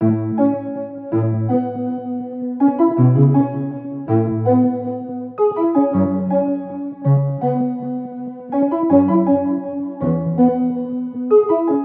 Tag: 81 bpm Pop Loops Synth Loops 1.99 MB wav Key : Unknown Ableton Live